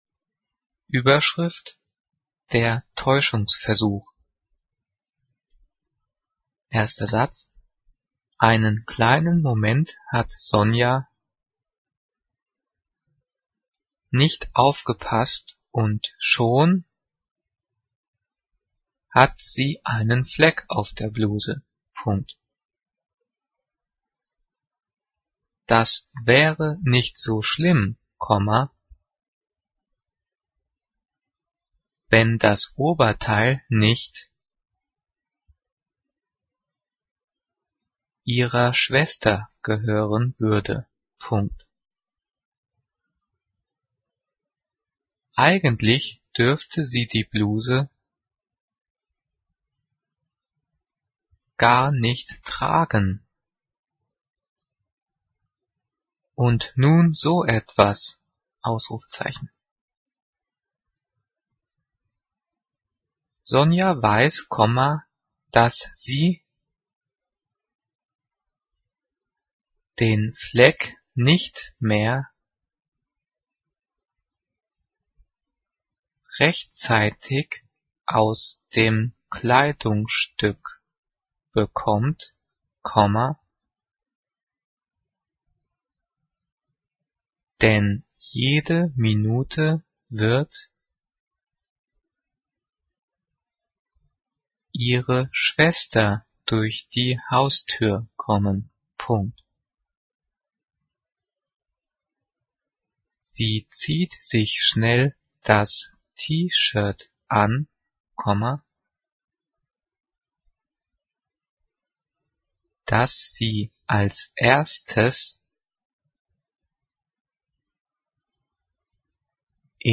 Die vielen Sprechpausen sind dafür da, dass du die Audio-Datei pausierst, um mitzukommen.
Übrigens, die Satzzeichen werden außer beim Thema "Zeichensetzung" und den Übungsdiktaten der 9./10. Klasse mitdiktiert.
Diktiert: